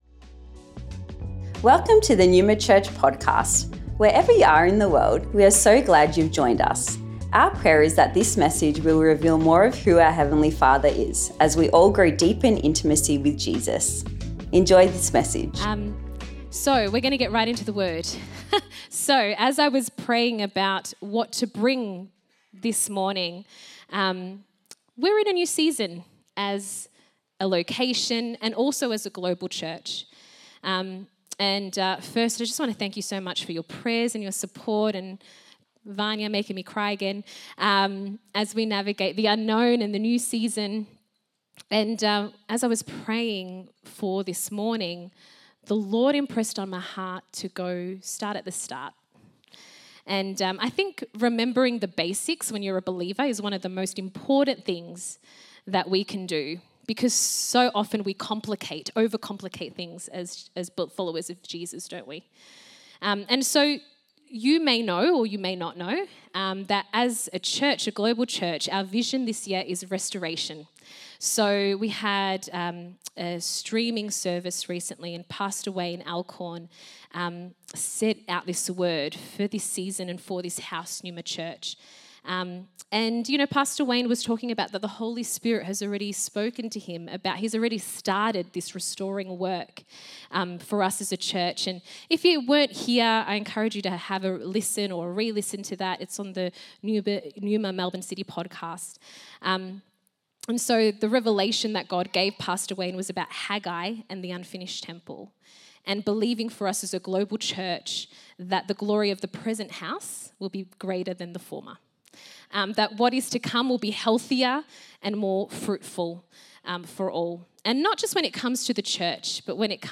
Originally recorded at Neuma Melbourne West on the 9th March 2025